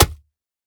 Minecraft Version Minecraft Version latest Latest Release | Latest Snapshot latest / assets / minecraft / sounds / block / packed_mud / break1.ogg Compare With Compare With Latest Release | Latest Snapshot